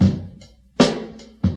84 Bpm Breakbeat G Key.wav
Free breakbeat sample - kick tuned to the G note. Loudest frequency: 841Hz
.WAV .MP3 .OGG 0:00 / 0:02 Type Wav Duration 0:02 Size 268,04 KB Samplerate 44100 Hz Bitdepth 16 Channels Stereo Free breakbeat sample - kick tuned to the G note.
84-bpm-breakbeat-g-key-aMq.ogg